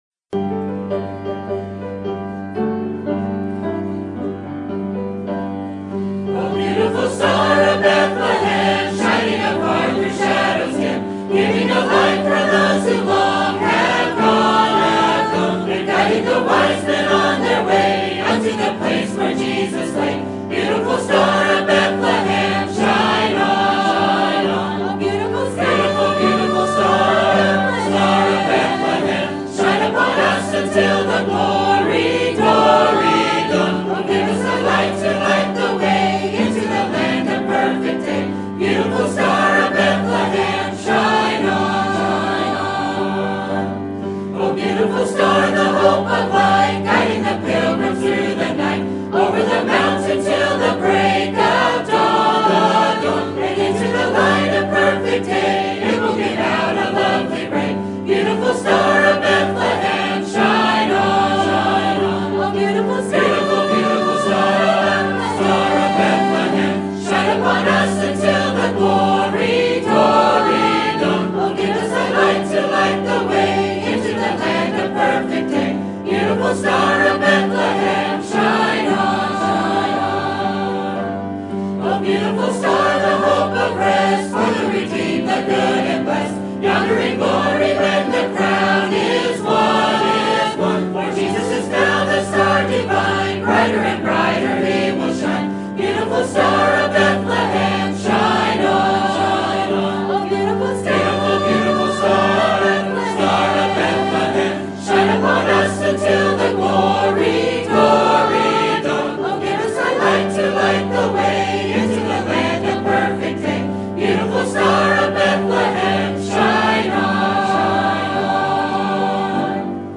Sermon Topic: General Sermon Type: Service Sermon Audio: Sermon download: Download (23.57 MB) Sermon Tags: Deuteronomy Tithes Blessing Giving